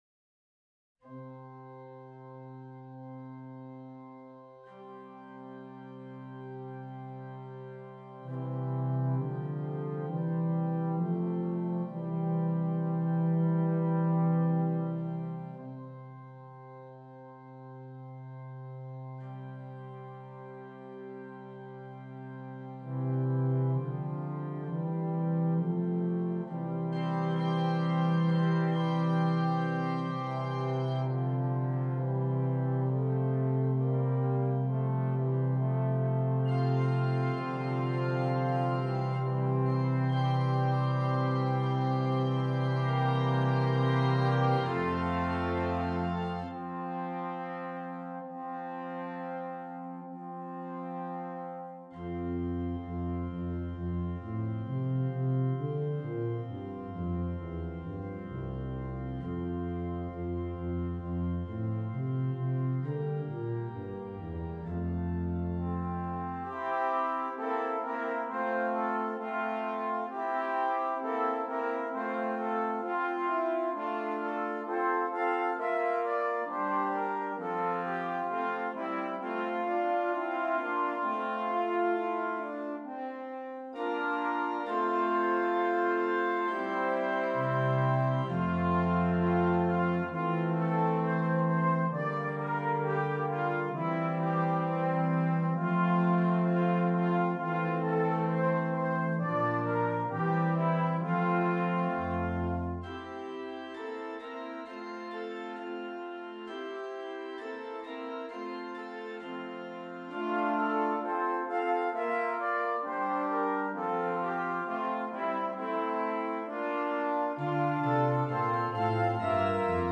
Traditional English Folk Song